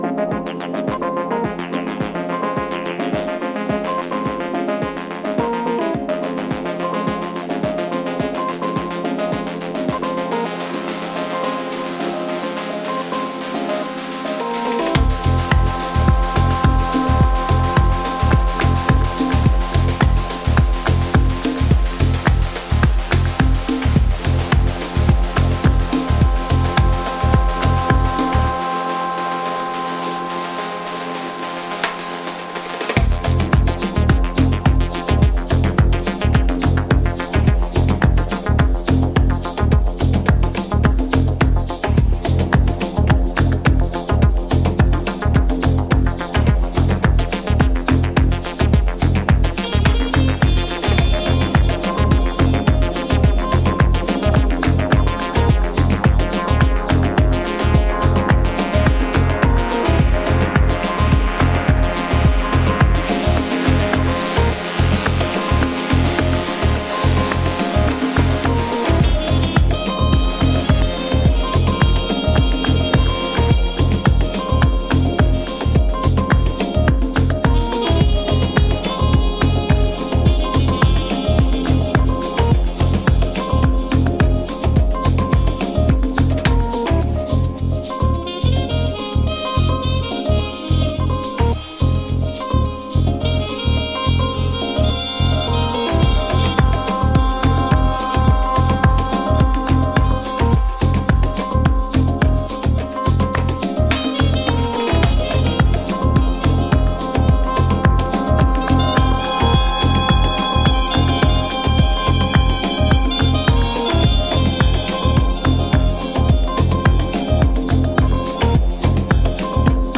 original and acidy